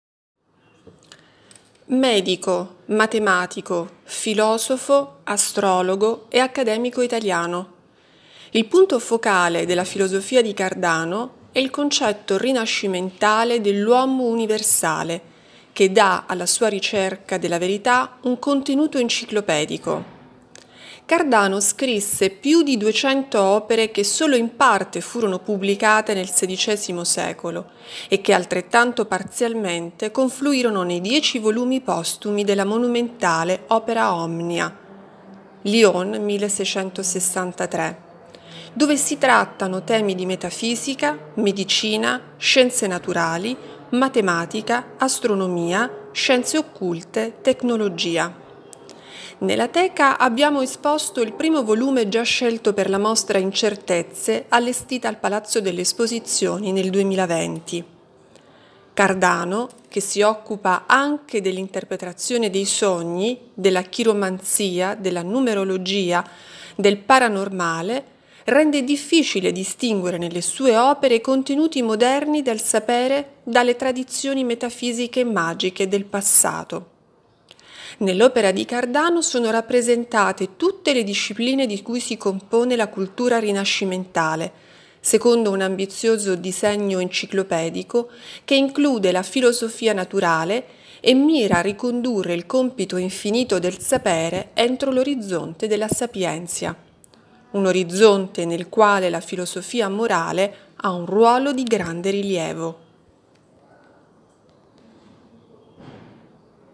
Audioguida Volumi Esposti nel 2022